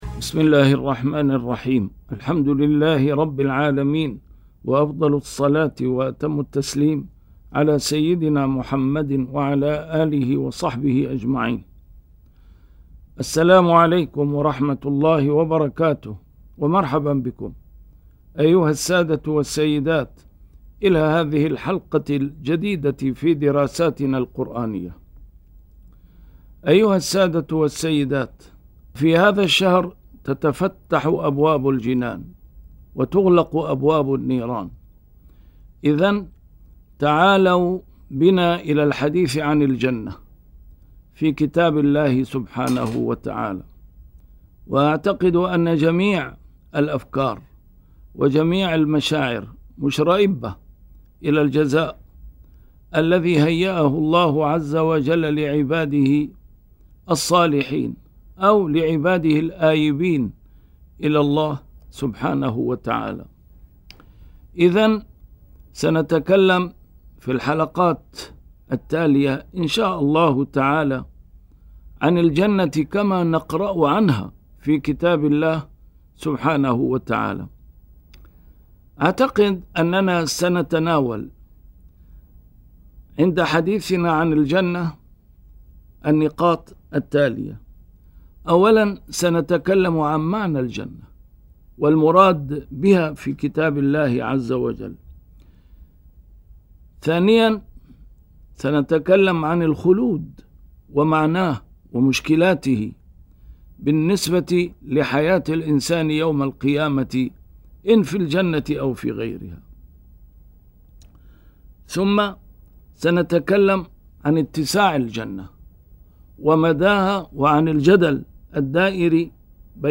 A MARTYR SCHOLAR: IMAM MUHAMMAD SAEED RAMADAN AL-BOUTI - الدروس العلمية - رحلة الخلود - 17 - الجنة